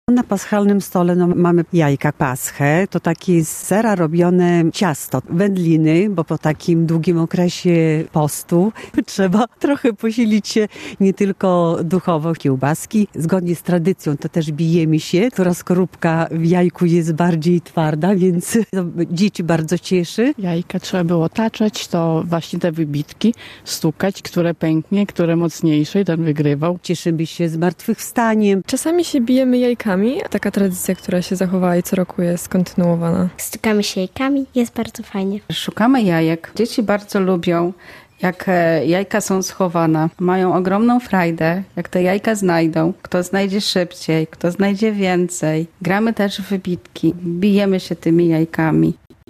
Przy świątecznym stole, gdzie królują potrawy mięsne i nabiał, najbardziej popularną zabawą są wybitki - relacja